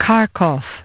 Transcription and pronunciation of the word "kharkov" in British and American variants.